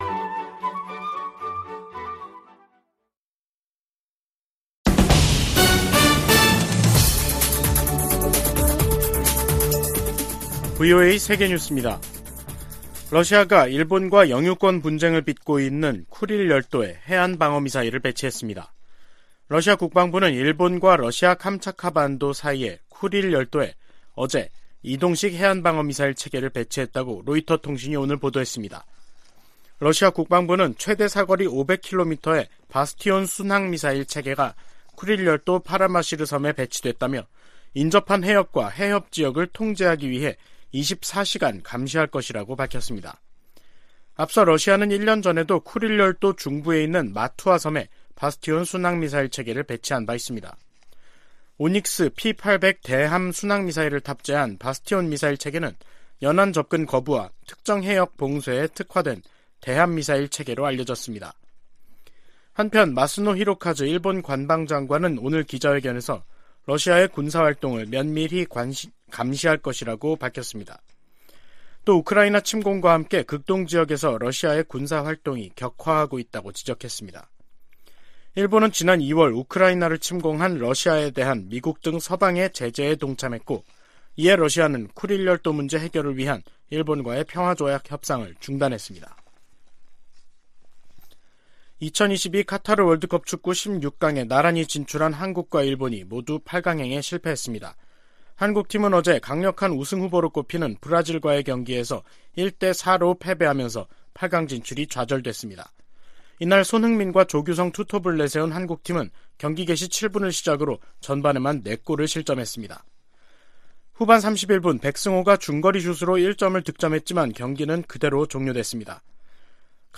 VOA 한국어 간판 뉴스 프로그램 '뉴스 투데이', 2022년 12월 6일 2부 방송입니다. 북한 김정은 정권이 미군과 한국 군의 통상적인 훈련을 구실로 이틀 연속 9.19 남북 군사합의를 위반하며 해상완충구역을 향해 포 사격을 가했습니다. 중국이 미중 정상회담 이후에도 여전히 북한 문제와 관련해 바람직한 역할을 하지 않고 있다고 백악관 고위 관리가 지적했습니다.